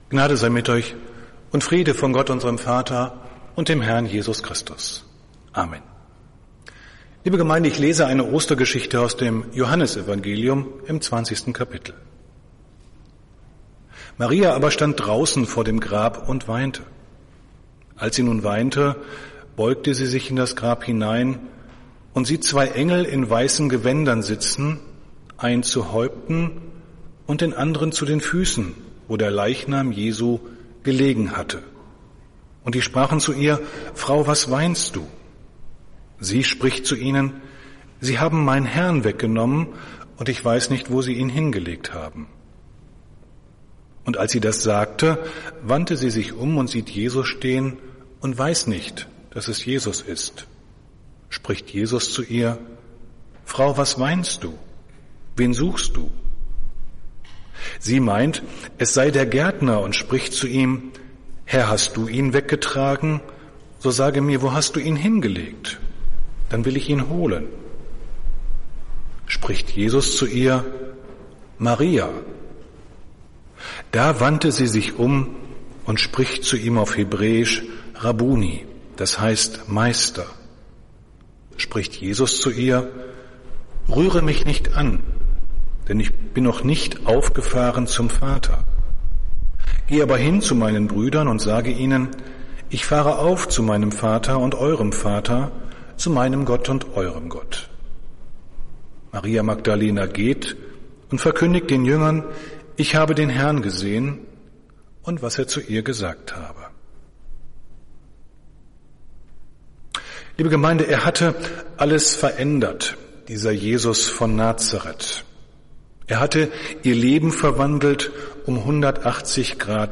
Predigt des Gottesdienstes aus der Zionskirche zum Ostermontag, 05.04.2021
Wir haben uns daher in Absprache mit der Zionskirche entschlossen, die Predigten zum Nachhören anzubieten.